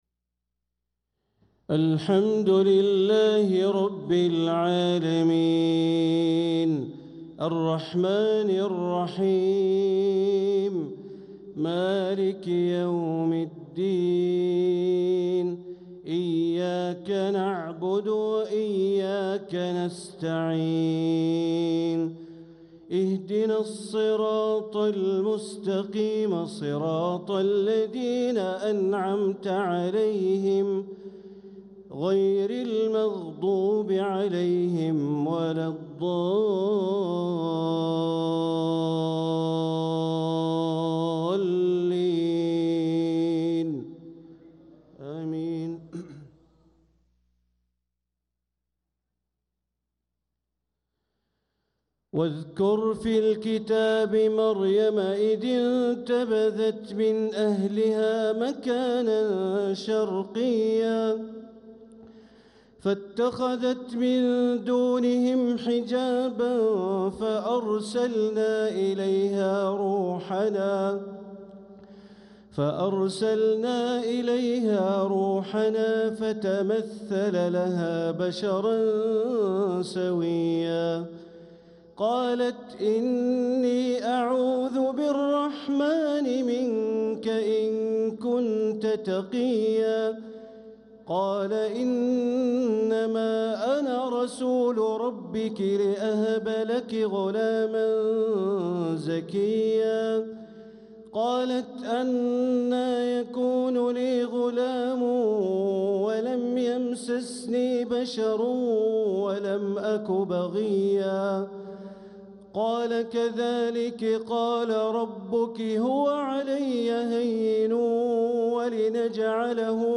صلاة العشاء للقارئ بندر بليلة 26 ربيع الأول 1446 هـ
تِلَاوَات الْحَرَمَيْن .